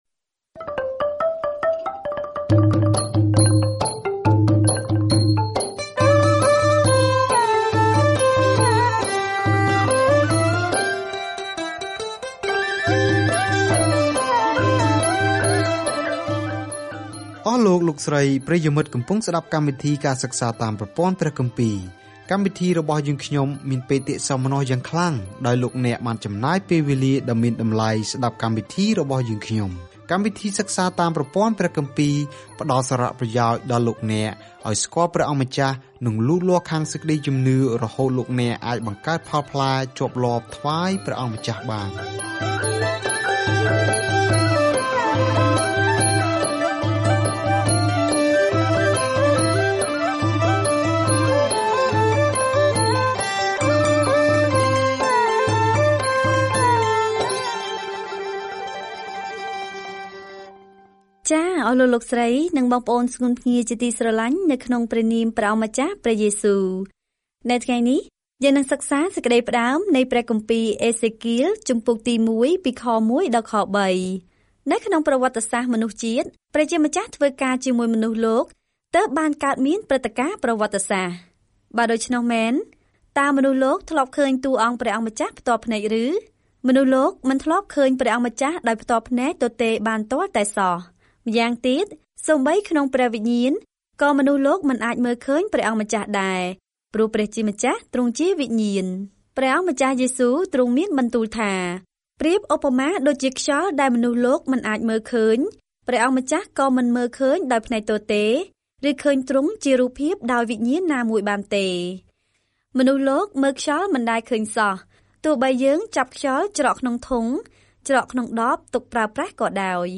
ប្រជាជននឹងមិនស្តាប់ពាក្យព្រមានរបស់អេសេគាលឲ្យត្រឡប់ទៅរកព្រះវិញទេ ដូច្នេះ ផ្ទុយទៅវិញគាត់បានបញ្ចេញពាក្យប្រស្នាដ៏អាក្រក់ ហើយវាបានចាក់ទម្លុះដួងចិត្តមនុស្ស។ ការធ្វើដំណើរប្រចាំថ្ងៃតាមរយៈអេសេគាល ពេលអ្នកស្តាប់ការសិក្សាជាសំឡេង ហើយអានខគម្ពីរដែលជ្រើសរើសពីព្រះបន្ទូលរបស់ព្រះ។